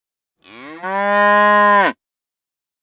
moo.wav